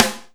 SNARE28.wav